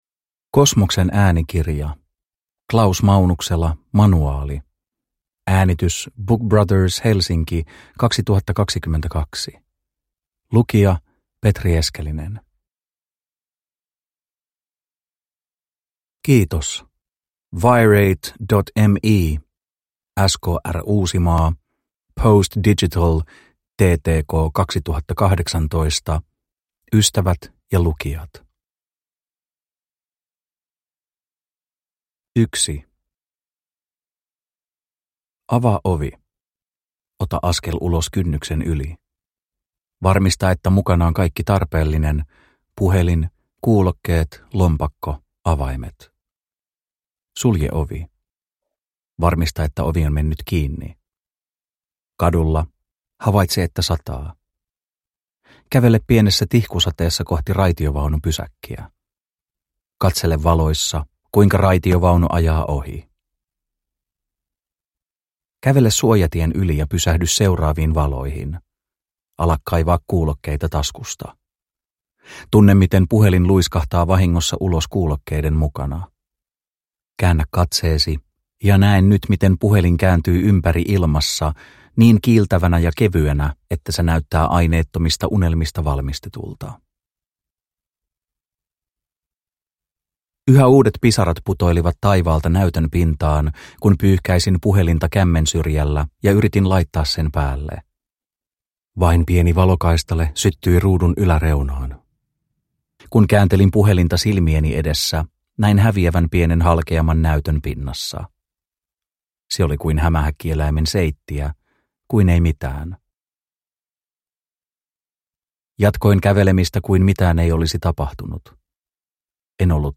Manuaali – Ljudbok – Laddas ner